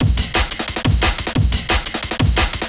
subsonic_amen2.wav